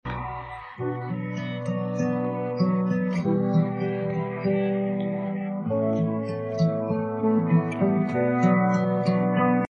分离后的伴奏：